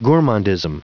Prononciation du mot gourmandism en anglais (fichier audio)